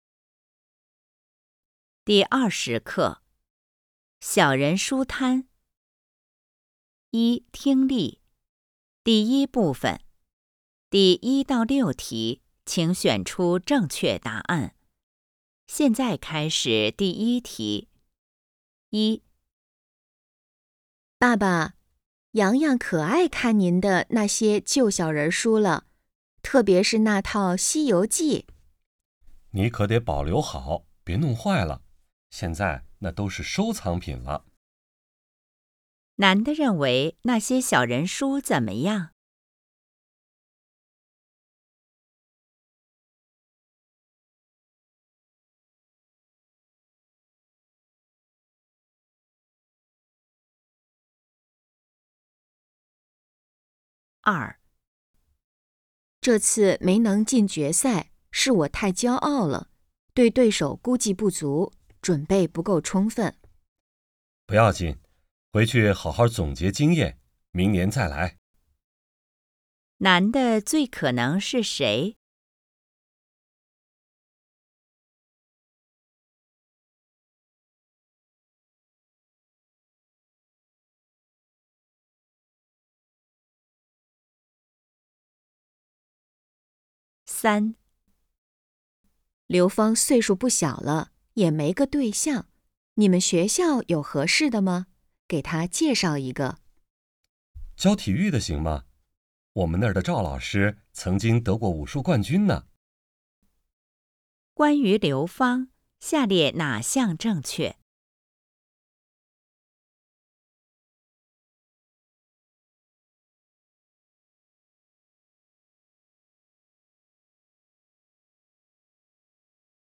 一、听力